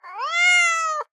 دانلود صدای گربه مخصوص گوشی از ساعد نیوز با لینک مستقیم و کیفیت بالا
جلوه های صوتی